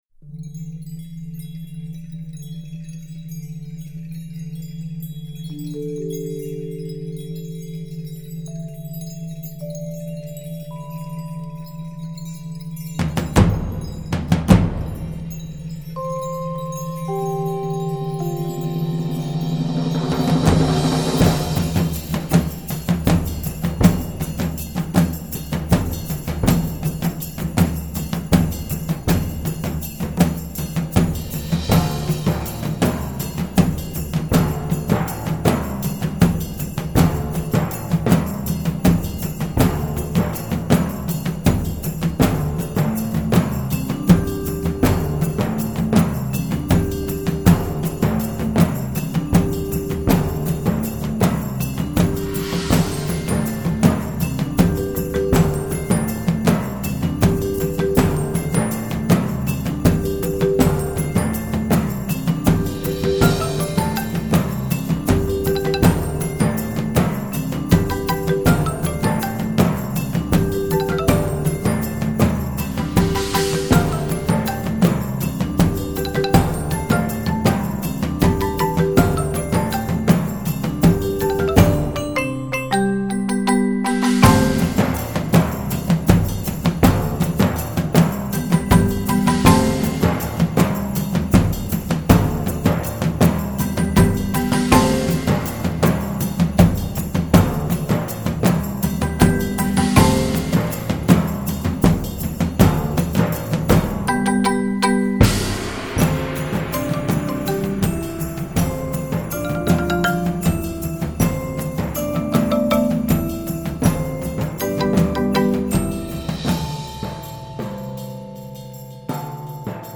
Voicing: 15-22 Percussion